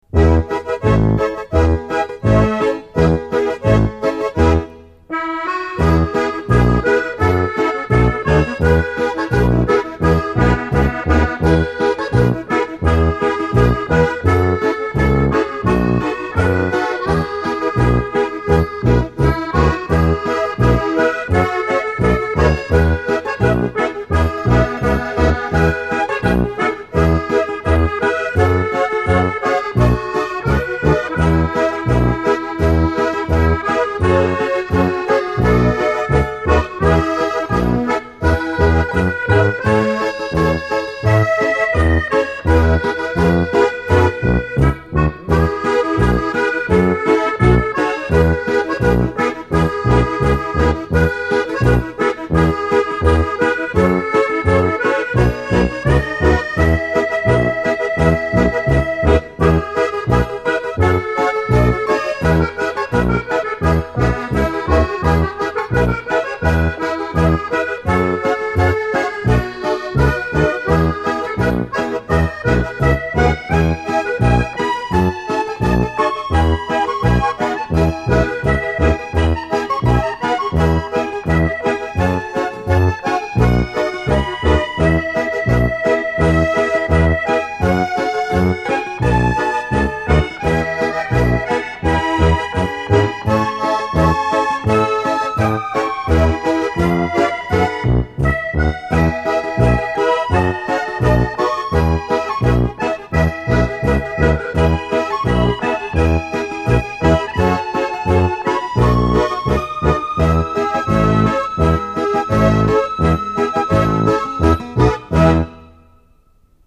悠扬低沉的手风琴随风飘逸，曲调婉转忧伤诉说爱的迷茫；
按键手风琴特殊演绎超激情，动听的旋律令人悲情意缠绵。